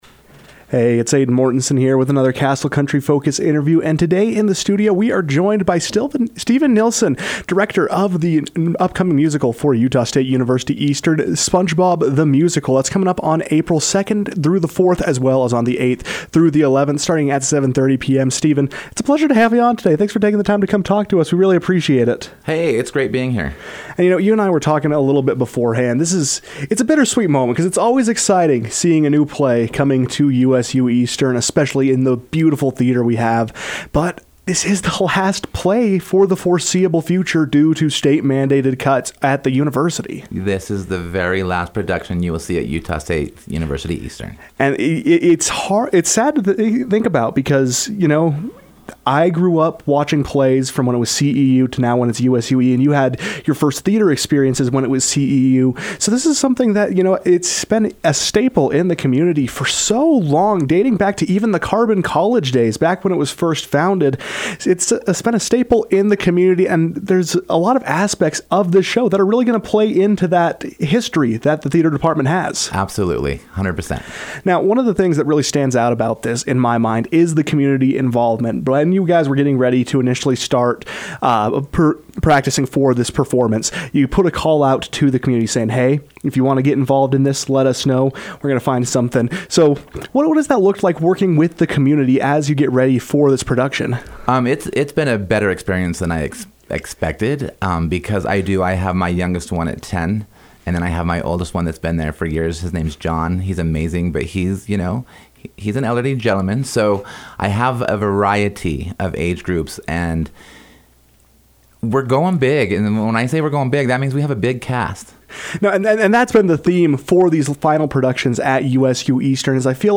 KOAL News